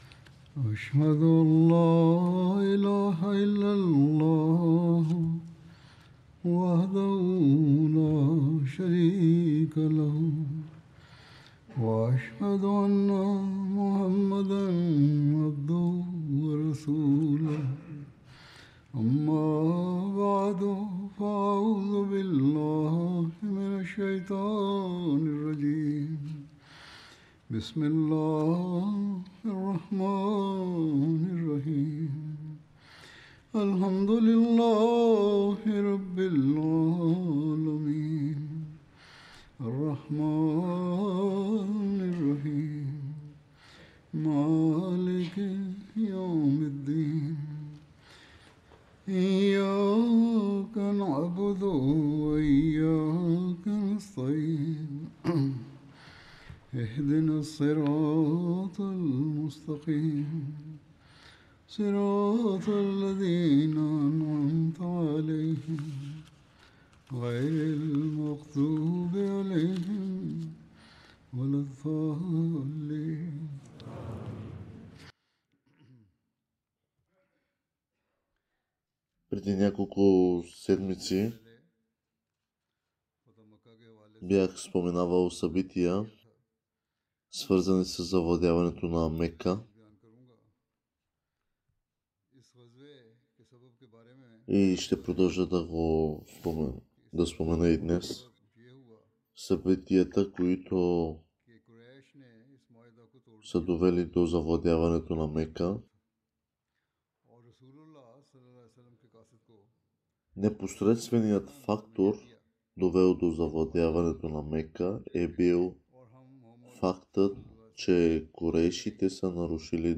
Bulgarian Friday Sermon by Head of Ahmadiyya Muslim Community
Bulgarian Translation of Friday Sermon delivered by Khalifatul Masih